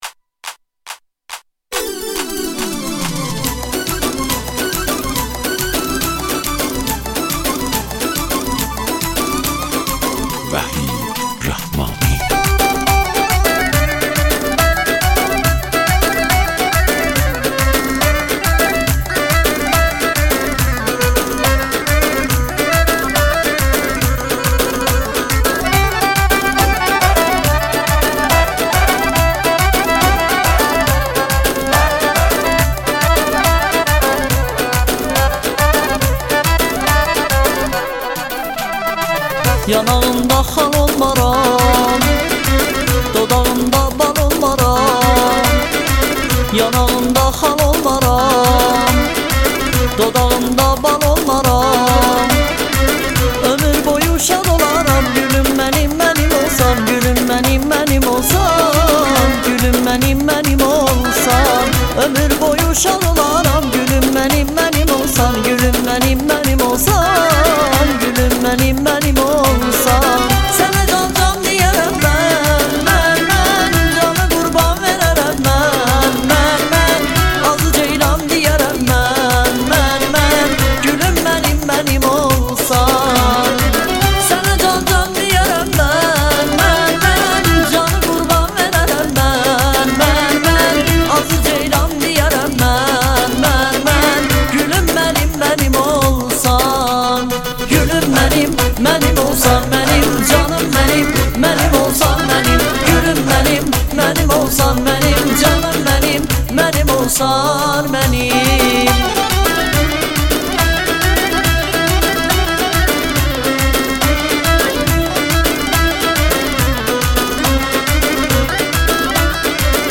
دانلود آهنگ آذری شاد عروسی ۱۴۰۰
دانلود آهنگ ترکی